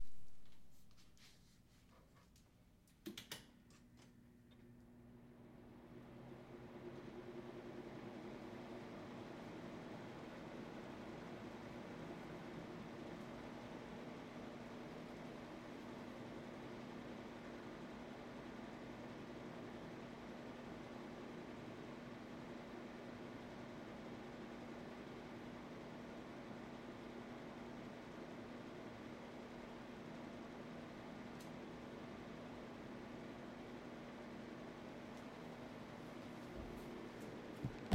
Звук вентилятора
Вентиляторный звук — как белый шум, создающий приятную фоновую атмосферу.
Включите вентилятор и оставьте его включенным
vklyuchite_ventilyator_i_ostavte_ego_vklyuchennim_gku.mp3